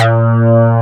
BSLEAD#3.wav